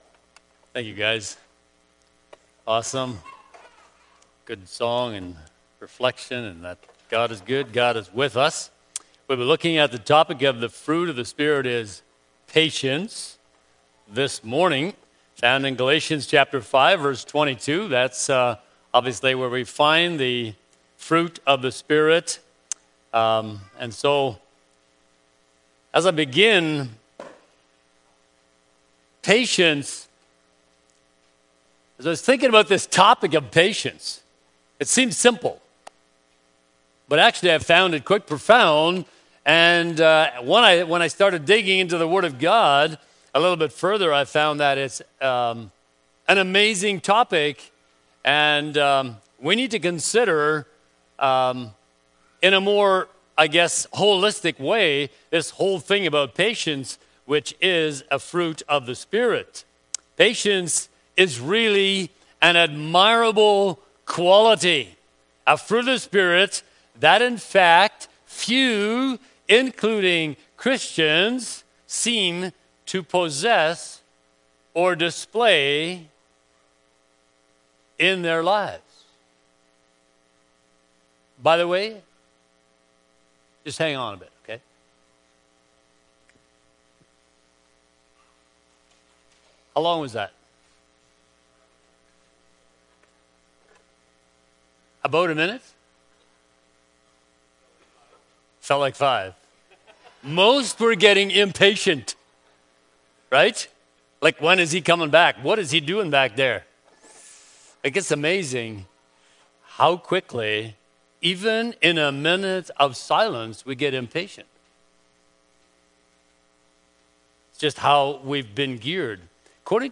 Bible Text: Galatians 5:22 | Preacher